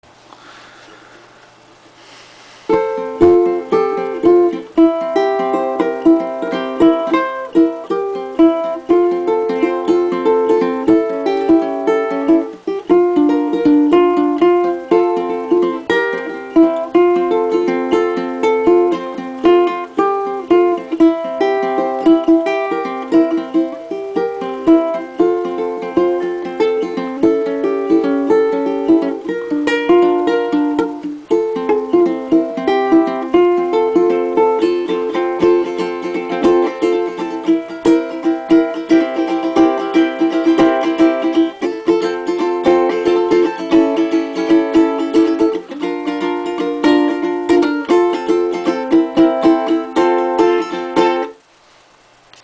Ukulélé tenor : TERMINE !!!
Et ben, pour quelqu'un de rouillé c'est pas mal :yes: et le son est vraiment sympa, j'aime bien :D
Le son est pas mal du tout.